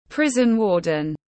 Người cai ngục tiếng anh gọi là prison warden, phiên âm tiếng anh đọc là /ˈwɔː.dən ˈprɪz.ən/.
Prison warden /ˈwɔː.dən ˈprɪz.ən/
Prison-warden.mp3